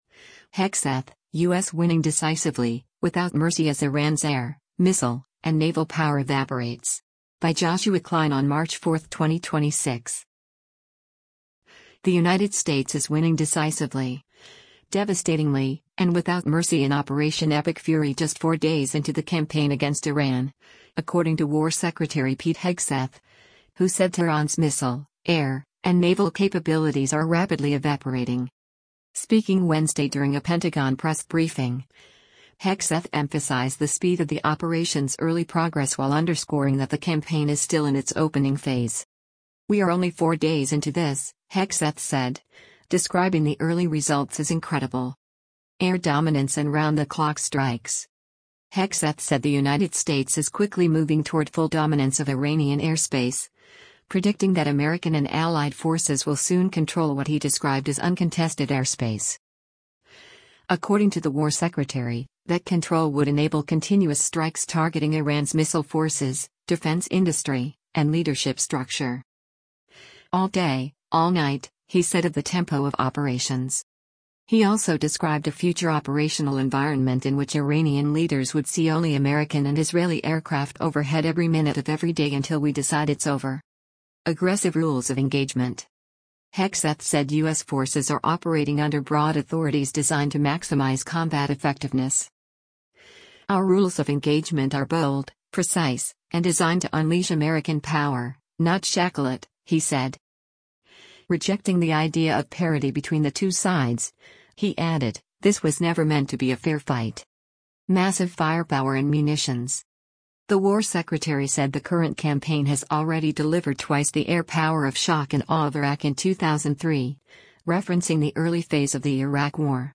Speaking Wednesday during a Pentagon press briefing, Hegseth emphasized the speed of the operation’s early progress while underscoring that the campaign is still in its opening phase.